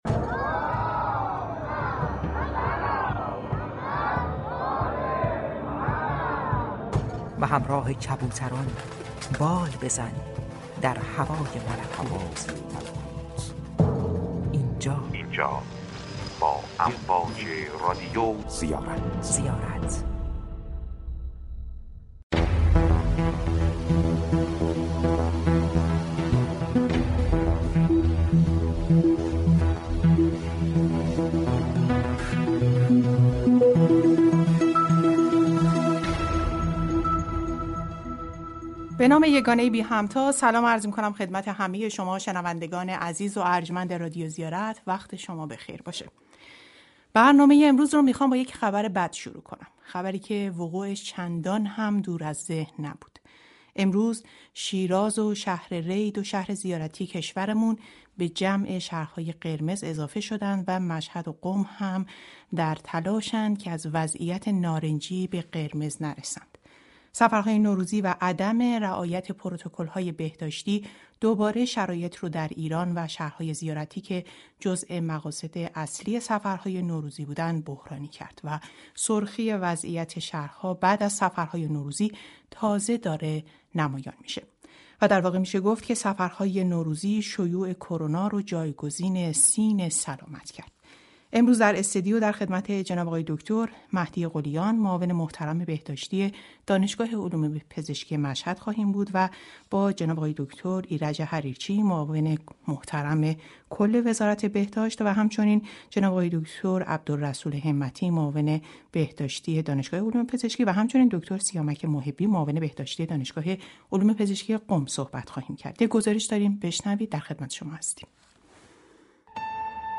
گفتگوی ویژه خبری رادیو زیارت امروز با موضوع سفرهای نوروزی علت تغییر رنگ شهرهای زیارتی به بررسی این اتفاق پرداخت.